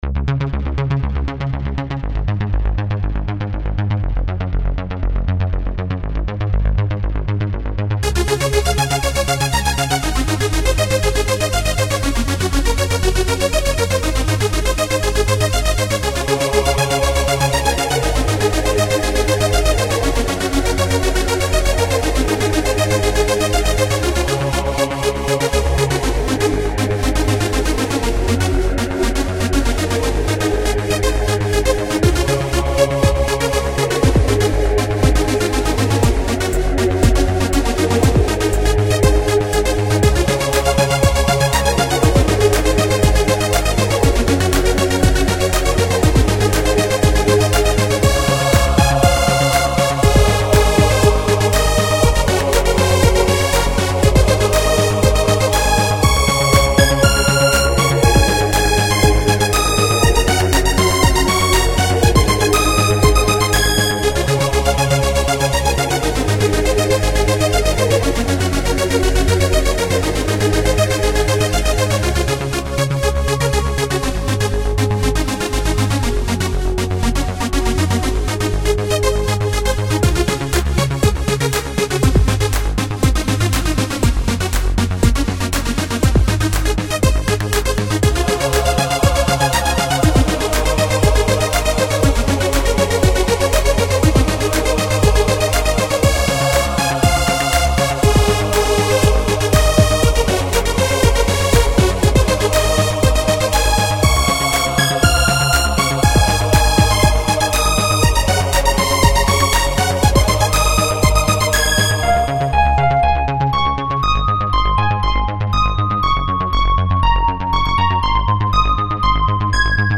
A musical composition
techno song
It is to be a upbeat happy song.